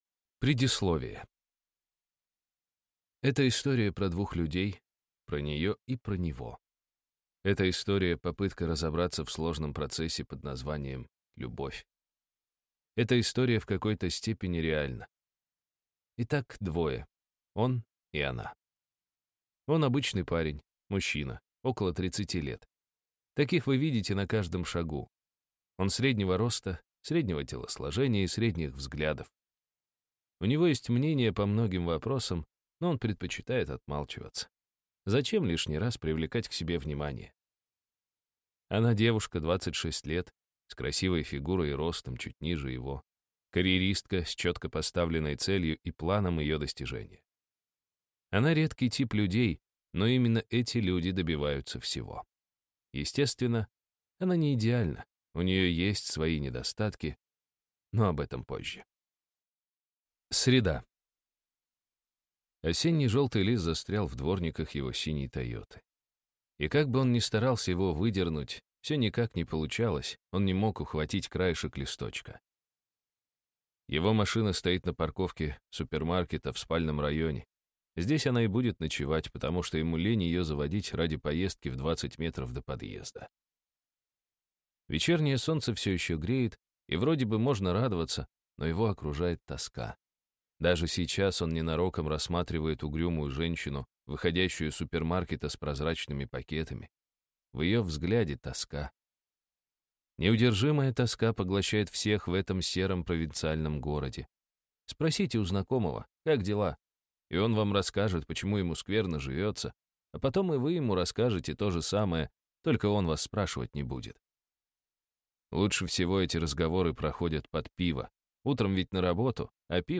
Аудиокнига Дешевая драма | Библиотека аудиокниг